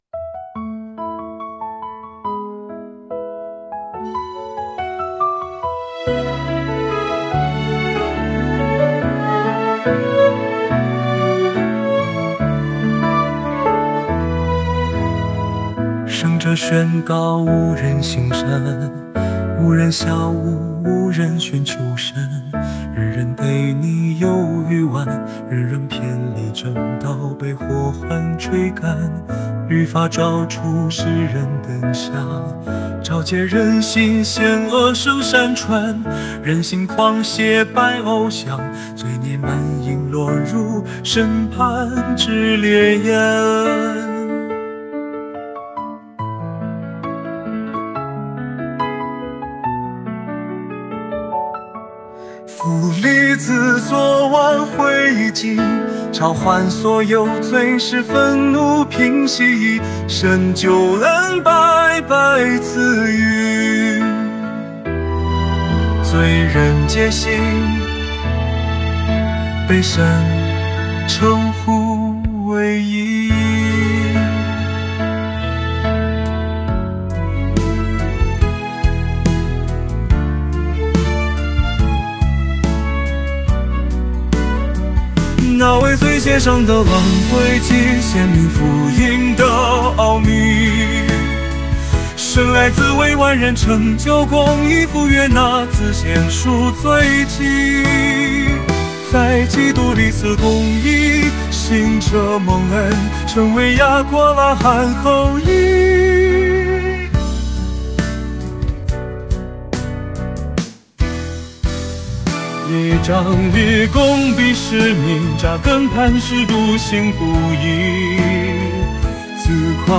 献唱/赞美新歌《显明与称为》